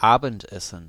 Ääntäminen
US GenAm: IPA : /ˈsʌpɚ/ RP : IPA : /ˈsʌpə/